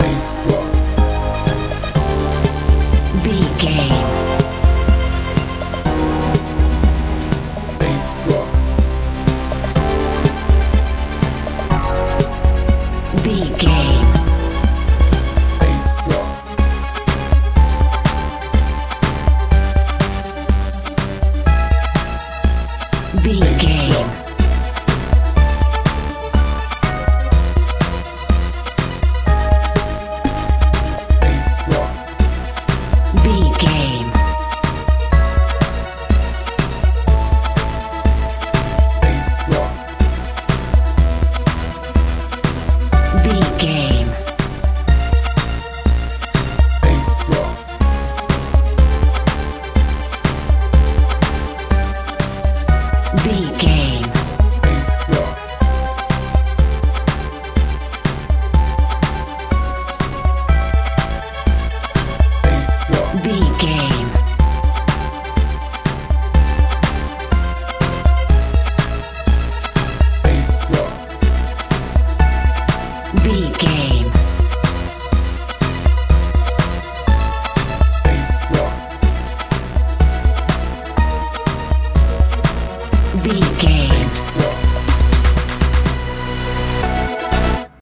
dance feel
Ionian/Major
A♭
mystical
strange
synthesiser
bass guitar
drums
80s
90s
high tech